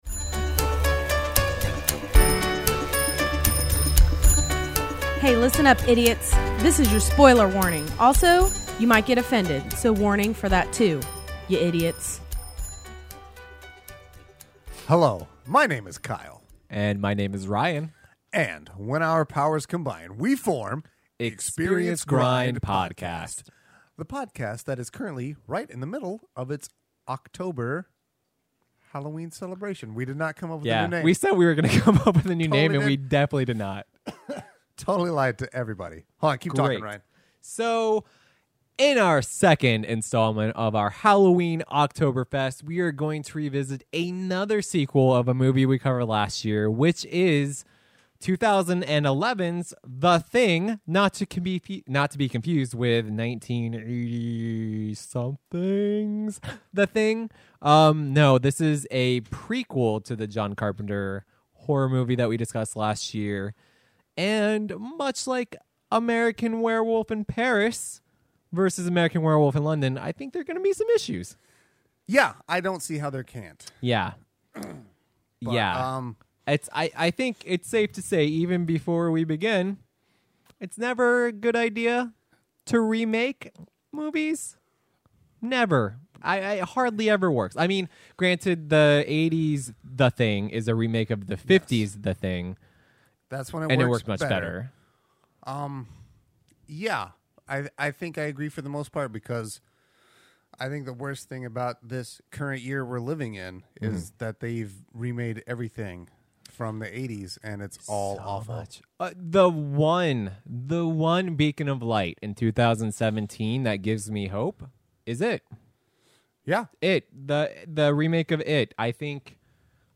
The boys attempt to detach themselves from that classic film and look at the prequel with an objective eye and evaluate it on it’s merits. this is also the second episode recorded in the new Experience Grind HQ 3.0 so you should notice some sound improvements.